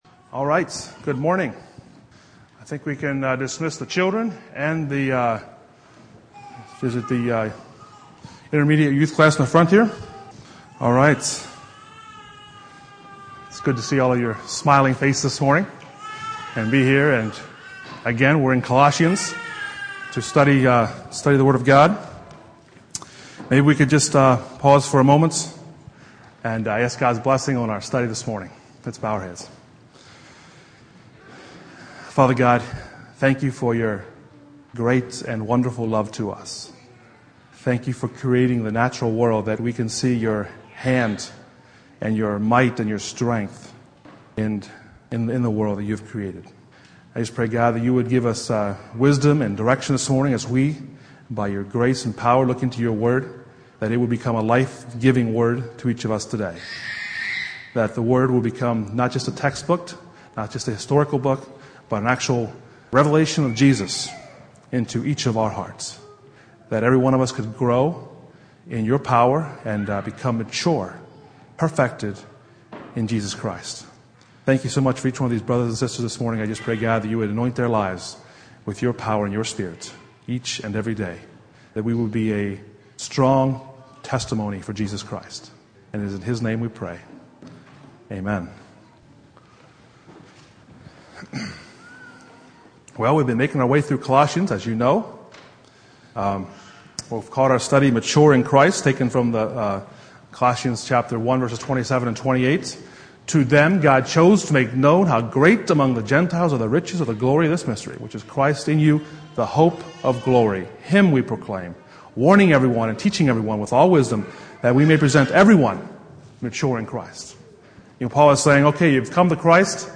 Passage: Colossians 1:24-29 Service Type: Sunday Morning